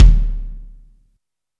INSKICK18 -R.wav